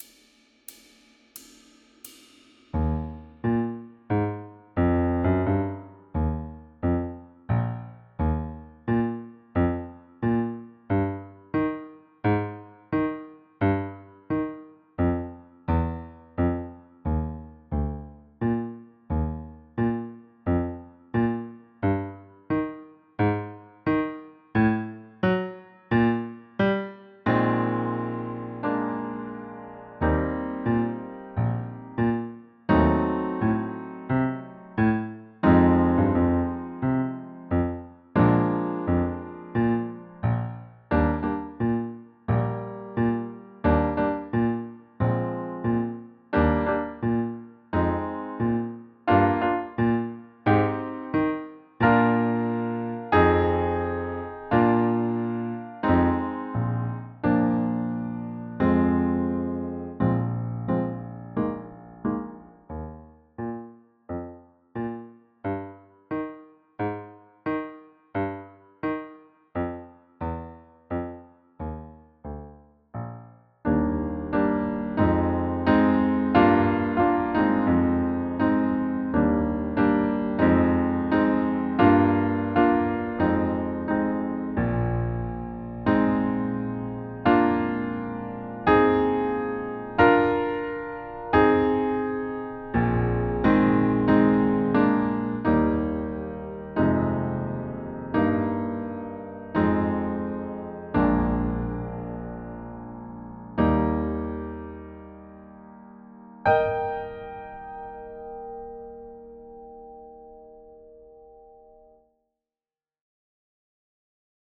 Puis, au dessous, vous trouverez un fichier de l’accompagnement de piano seul qui vous permettra de jouer (après quelques jours de travail) votre morceau accompagné !